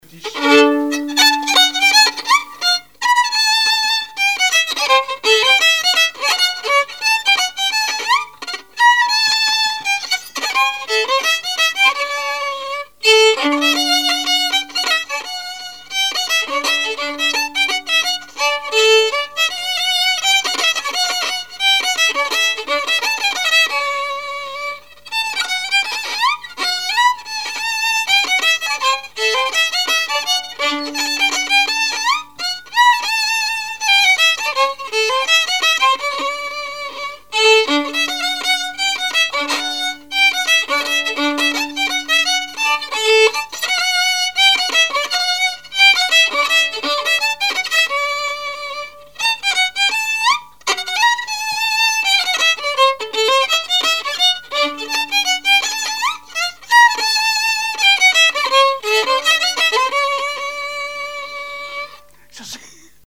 danse : scottich trois pas
Répertoire musical au violon
Pièce musicale inédite